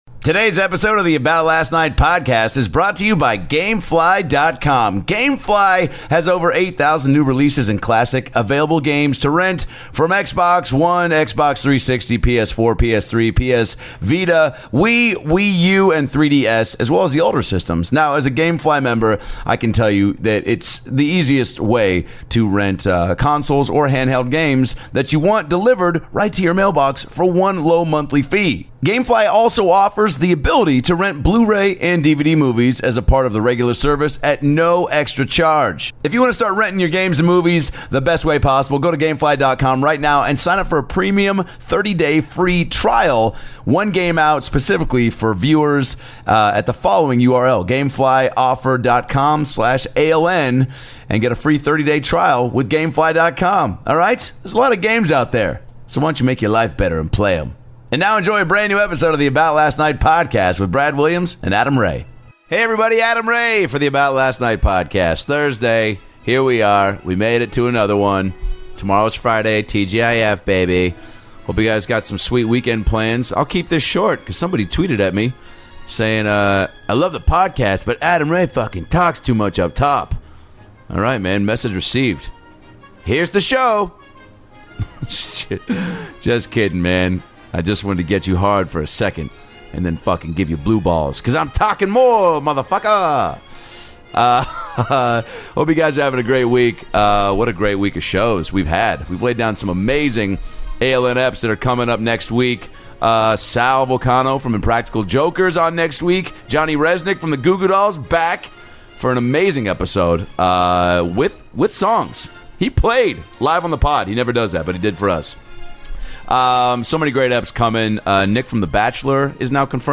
ALN - Live from Vegas!
Recorded at the Crapshoot Comedy Festival in Los Vegas